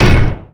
ham_hit.wav